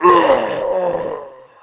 dead_1.wav